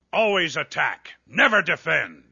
角色语音